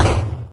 Techmino/media/effect/chiptune/clear_1.ogg at fac020db8ca5c3063fcca1ef07fc07f021c446ec
clear_1.ogg